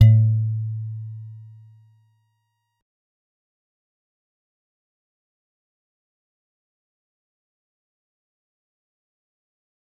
G_Musicbox-A2-mf.wav